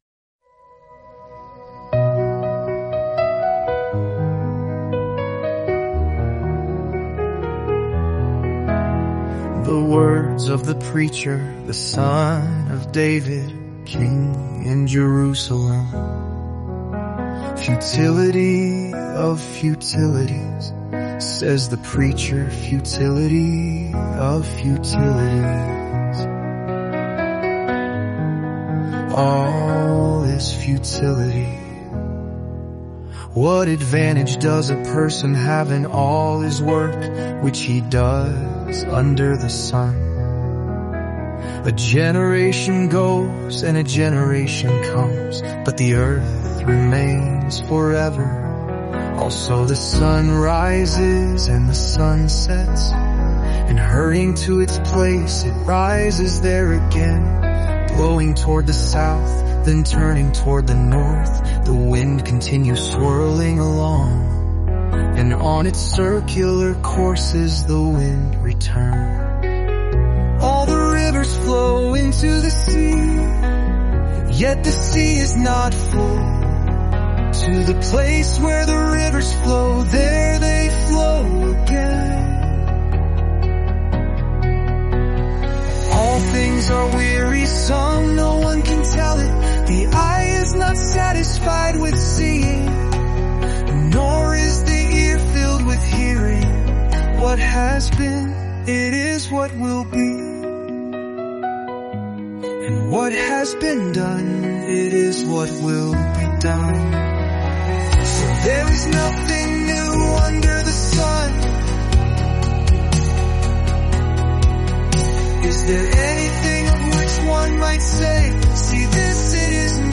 Experience the beauty and wisdom of Song of Songs and Ecclesiastes in just 7 days through word-for-word Scripture songs.